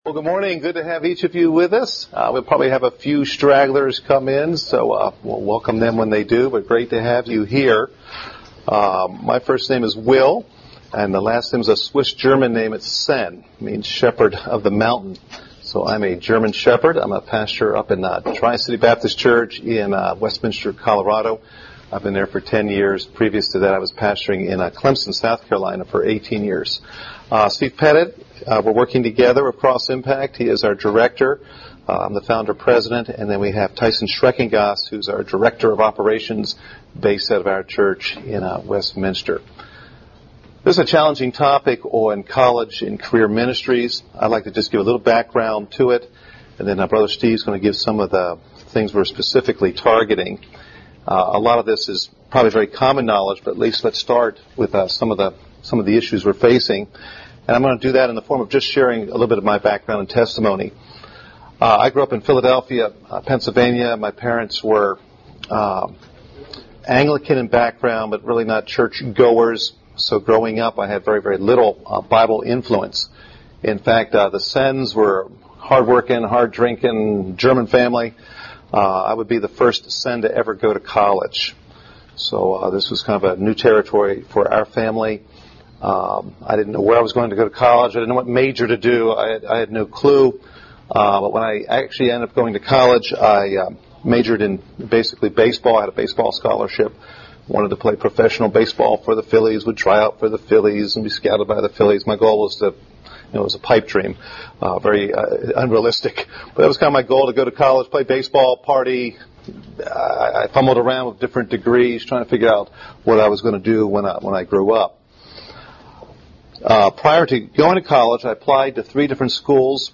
Workshop